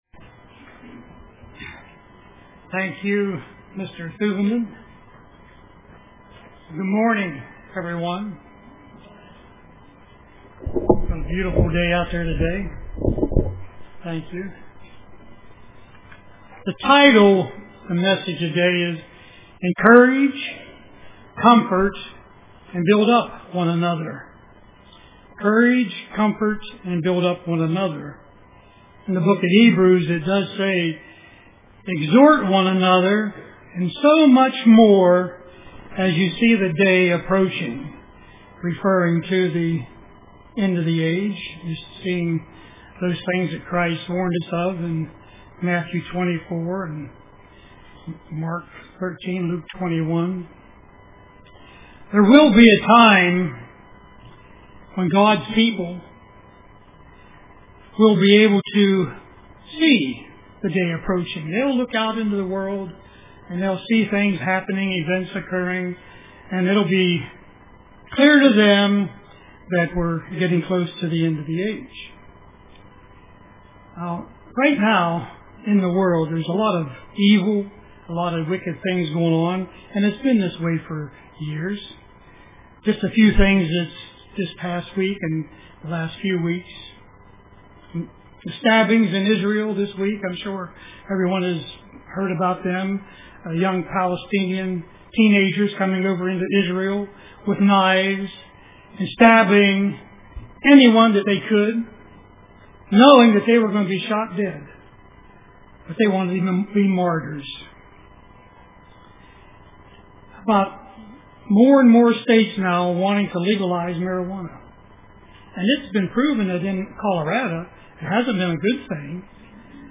Print Encourage, Comfort, and Build-up One Another UCG Sermon Studying the bible?